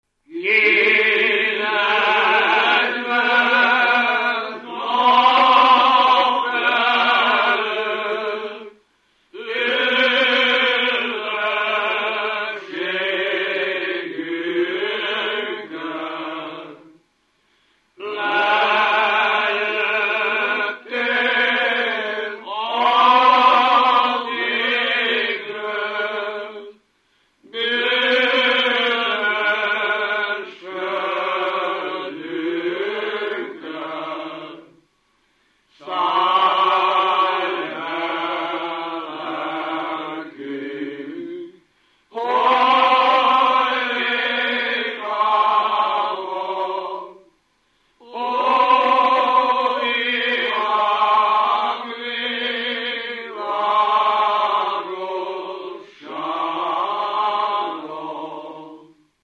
Felföld - Abaúj-Torna vm. - Fáj
Stílus: 5. Rákóczi dallamkör és fríg környezete